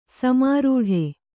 ♪ samārūḍhe